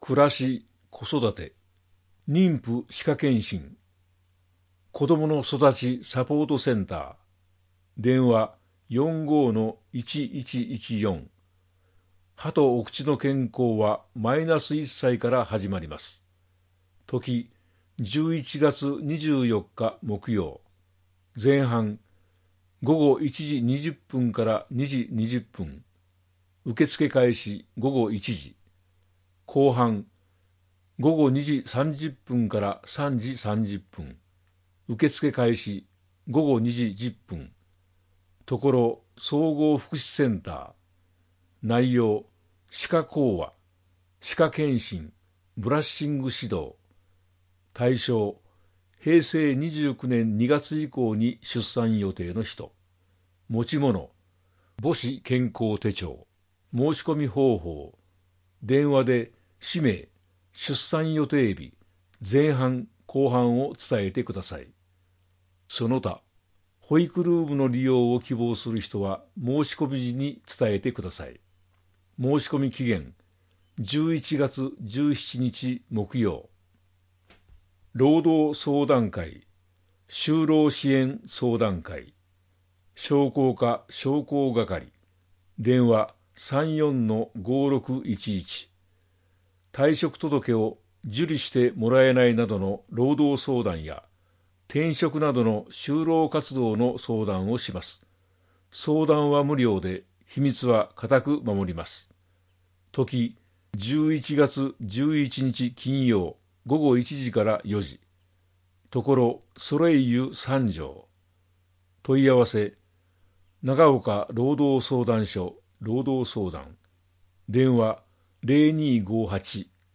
広報さんじょうを音声でお届けします。